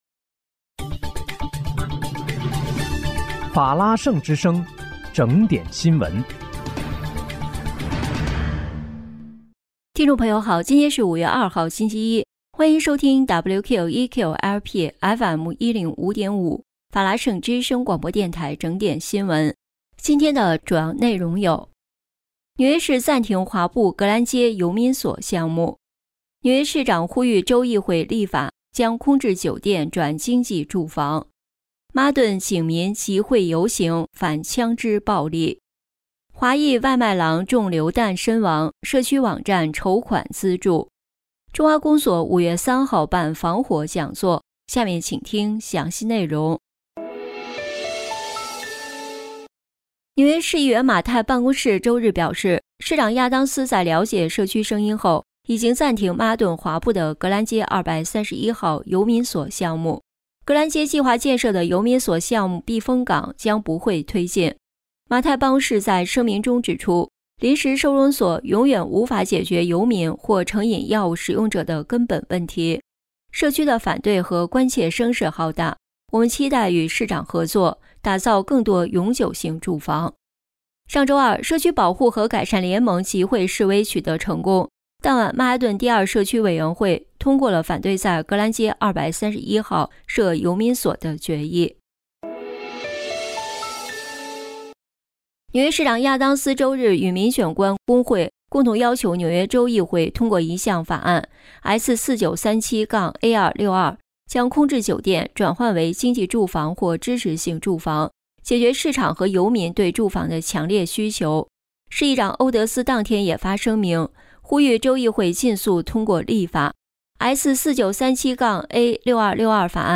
5月2日（星期一）纽约整点新闻
听众朋友您好！今天是5月2号，星期一，欢迎收听WQEQ-LP FM105.5法拉盛之声广播电台整点新闻。